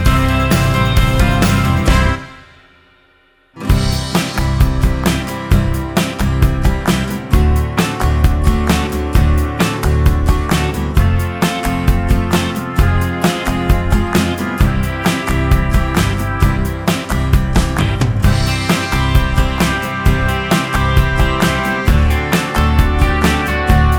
no Backing Vocals Jazz / Swing 4:09 Buy £1.50